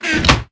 chestclosed.ogg